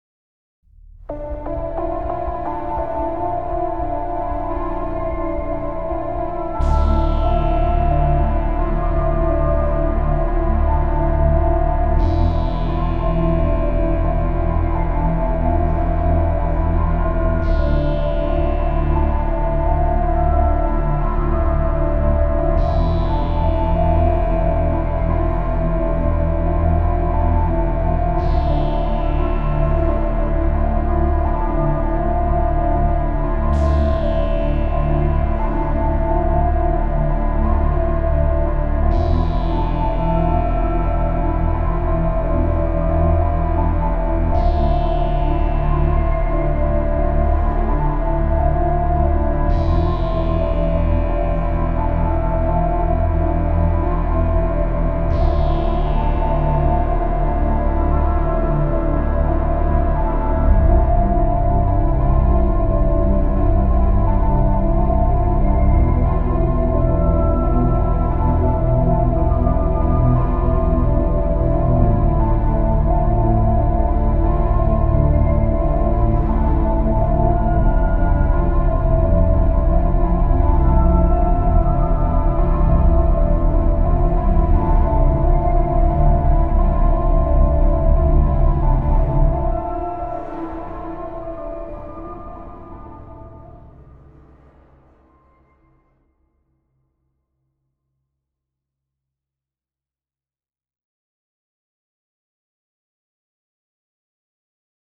Walking home in the moonlight with some sci-fi vibes.